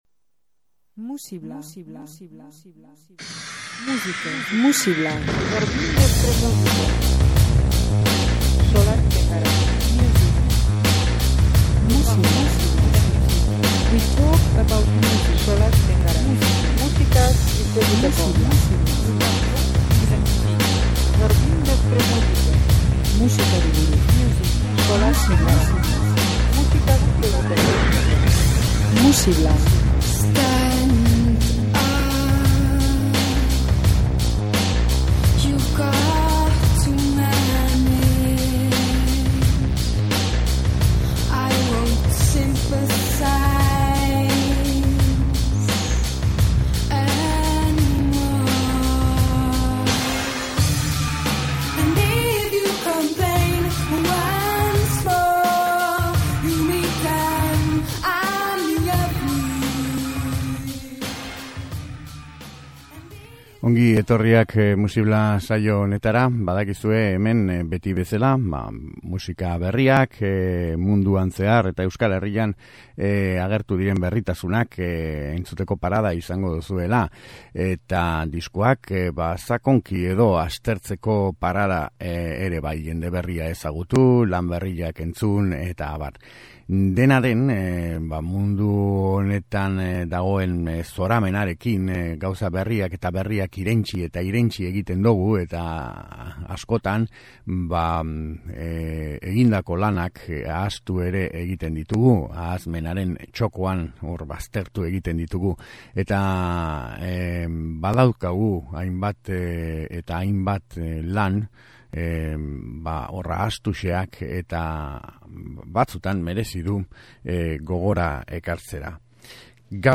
soinu elektronikoak